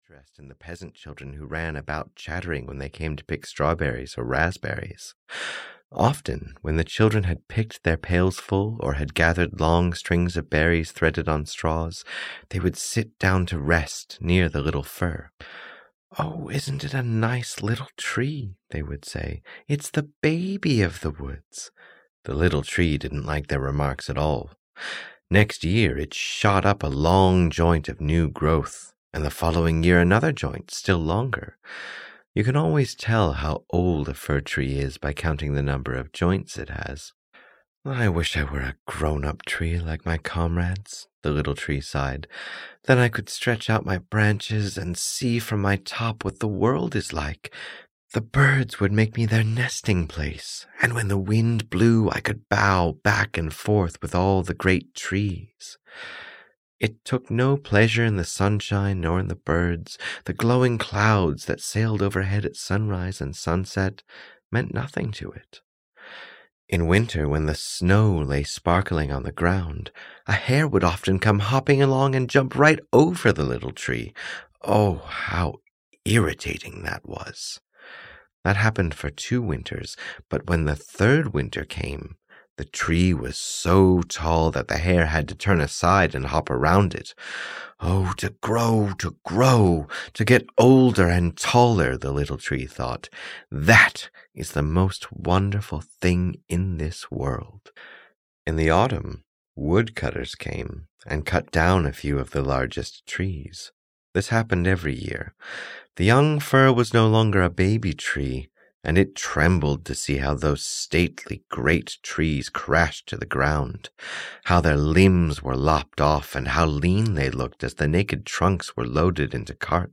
The Fir Tree (EN) audiokniha
Ukázka z knihy